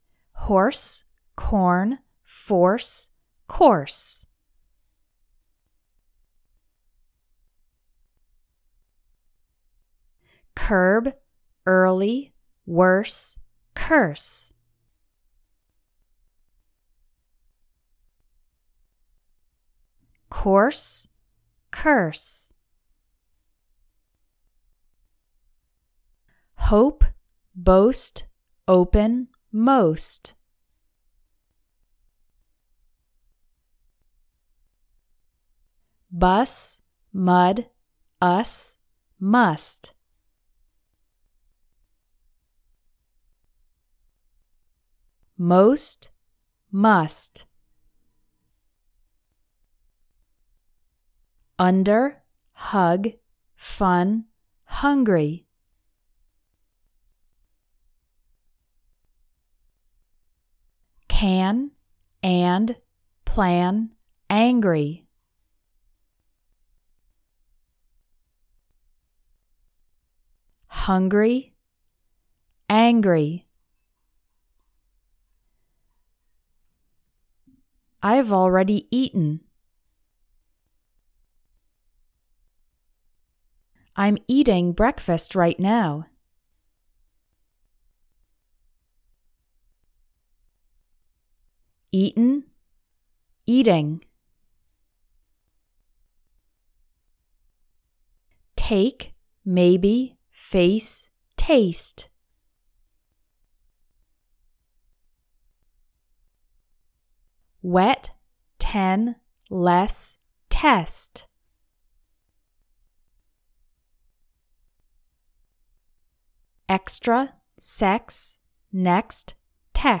Words with similar sounds
• The “t” is very soft and the “en” is barely heard in “eaten.”
The second syllable is more pronounced in “eating”
• The first is pronounced with the stress on the first syllable: COU-sin
There’s a small “w” sound: cwi-ZEEN